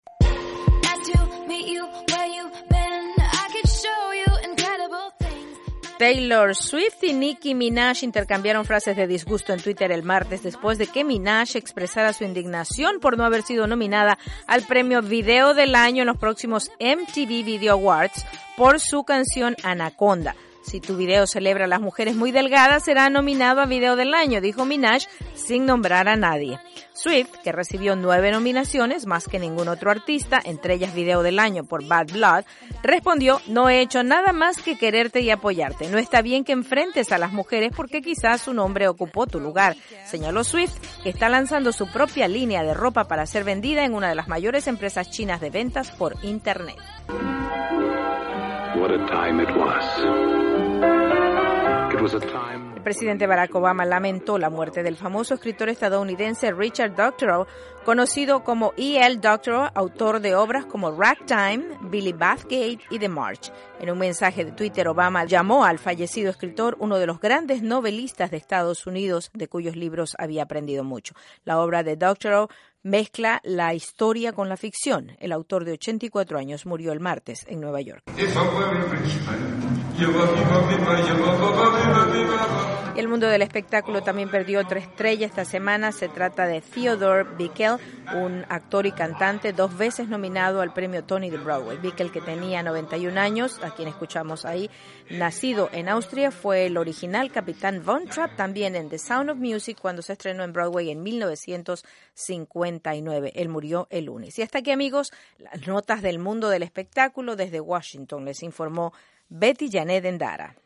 Durante la jornada de este miércoles un grupo de parlamentarios españoles y latinoamericanos llegará a Venezuela para constatar la situación de los presos políticos. Desde Caracas informa